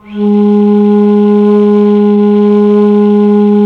FLT ALTO F00.wav